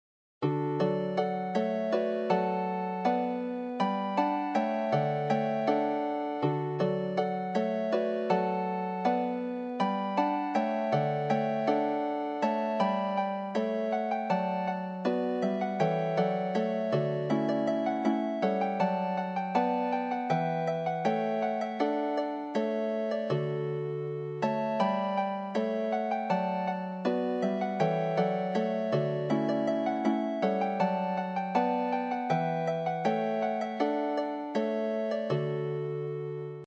for lever harp and pedal harps
midi generated file
Arrangements assume tuning in E flat.